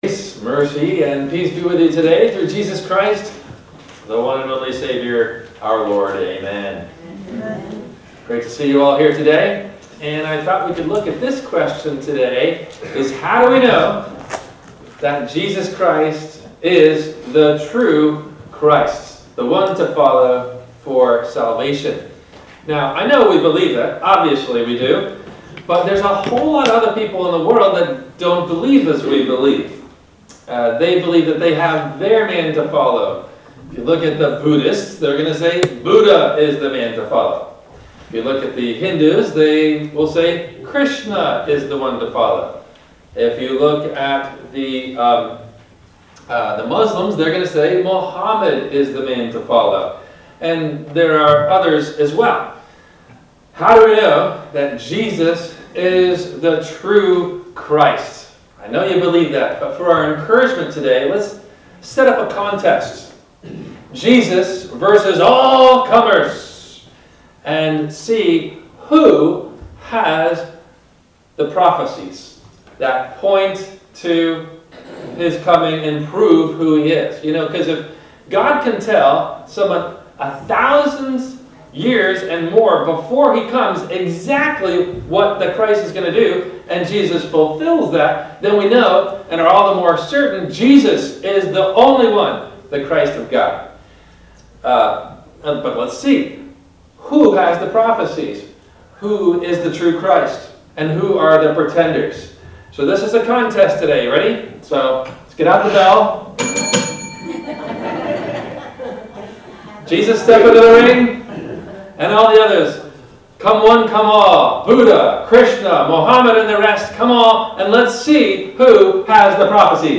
How To Be Sure Jesus Is The True Messiah – WMIE Radio Sermon – December 18 2023